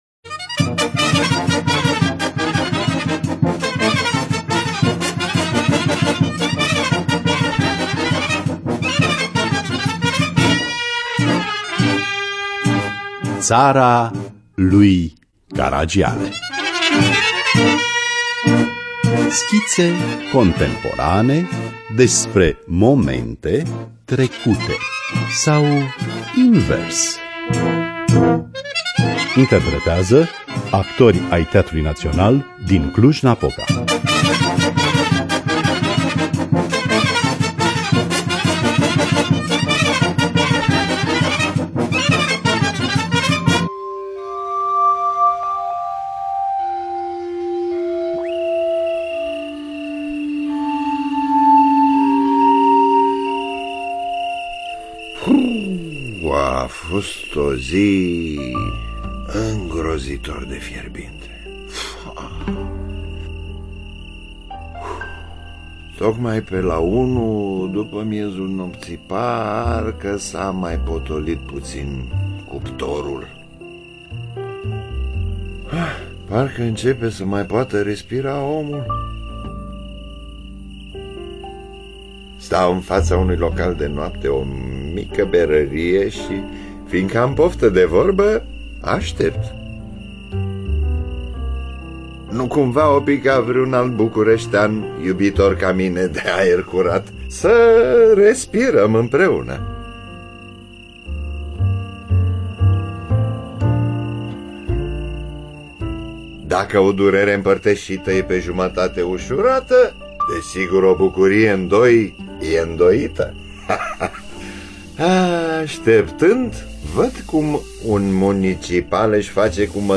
Actorii fac parte din colectivul Teatrului Naţional din Cluj-Napoca.